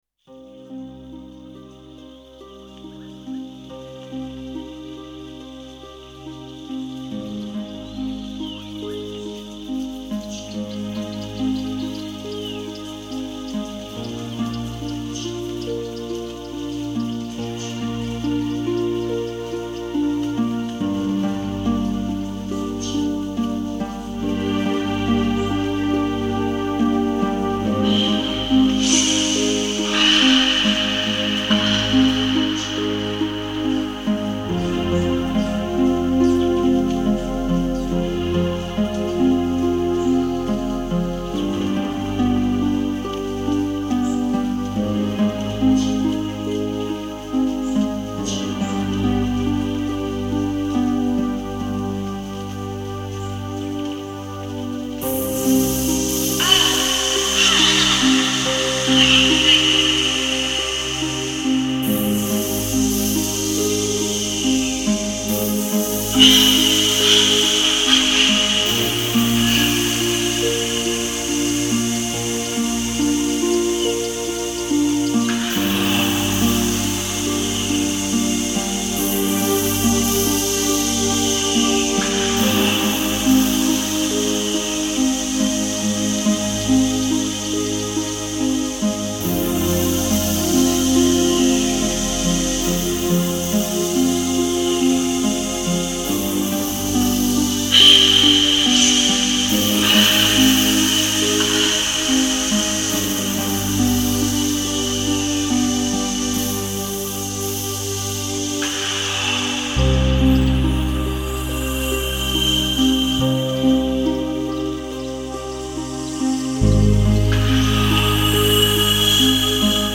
Прекрасная музыка, глубокая, как будто в Юджунгли теряешся!